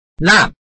臺灣客語拼音學習網-客語聽讀拼-饒平腔-入聲韻
拼音查詢：【饒平腔】lab ~請點選不同聲調拼音聽聽看!(例字漢字部分屬參考性質)